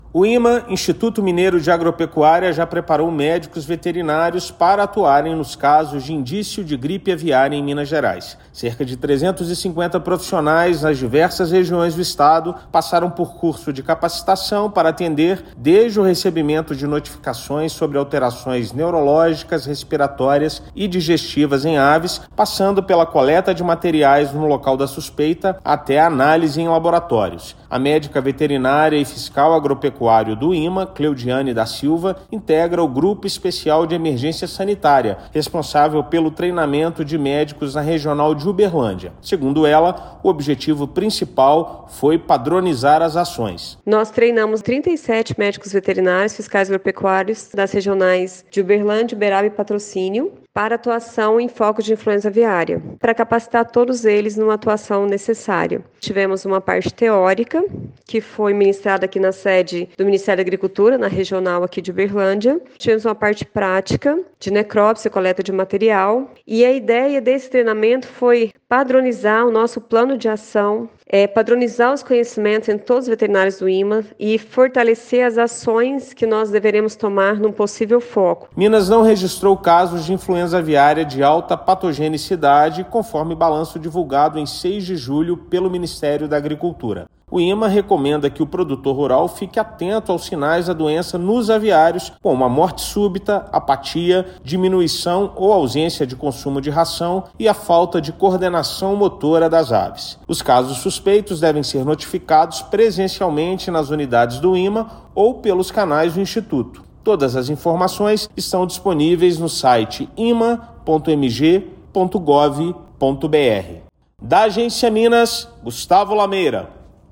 Aproximadamente 350 profissionais foram capacitados em oito edições do curso, nas diversas regiões de Minas, com conteúdo teórico-prático. Ouça matéria de rádio.